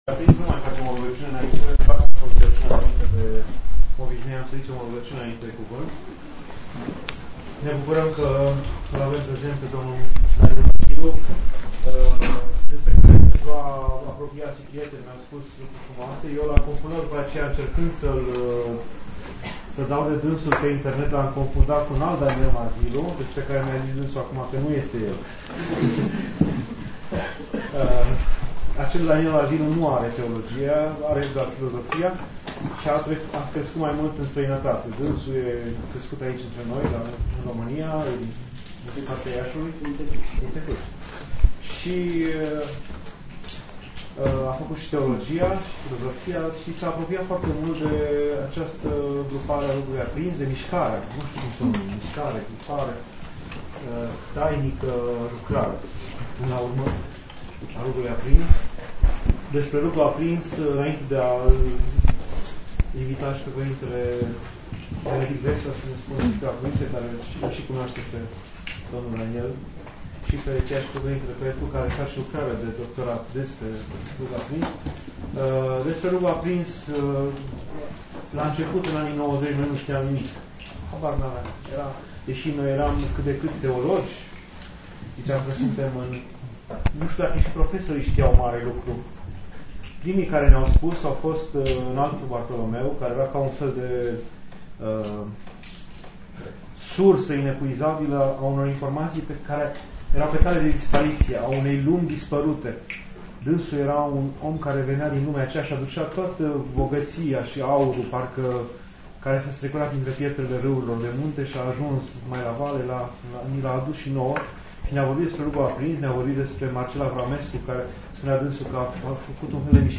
Cuvânt de la Catedrală